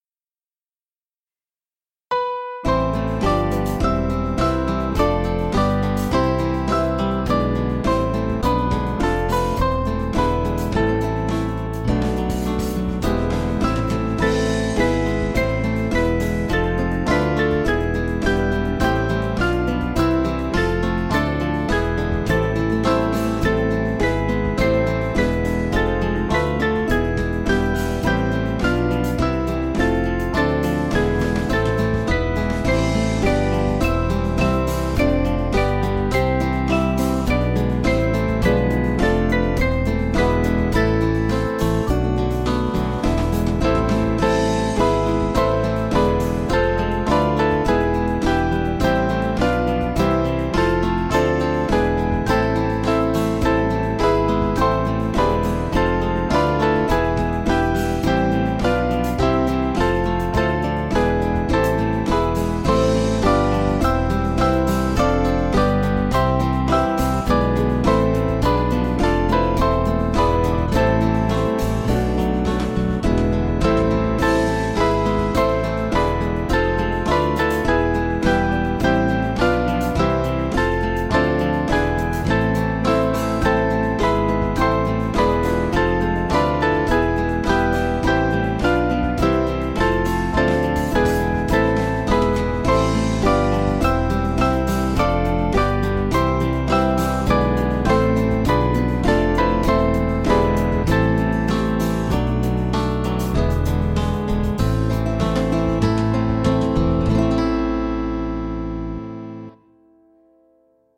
Small Band
(CM)   3/Am